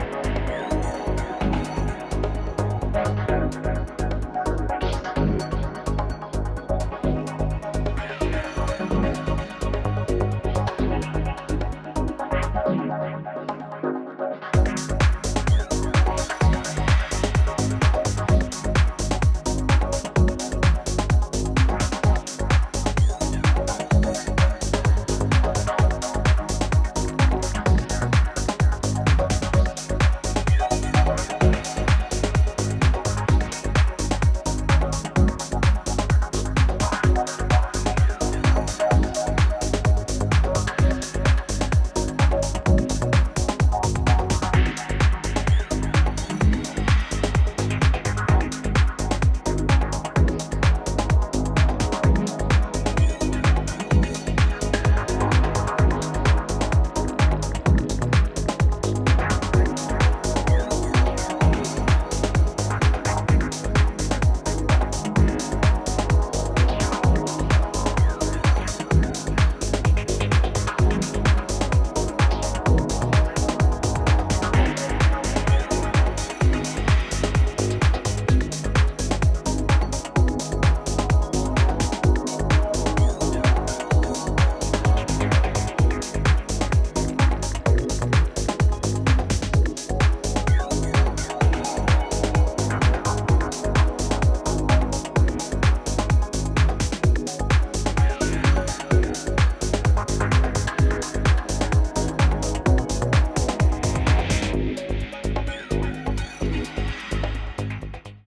ここでは、流麗なシンセワークや推進力溢れるグルーヴを駆使したクールなミニマル・テック・ハウスを展開しています。
アクアティックですらあるメロディーの構成でフロアを魅了します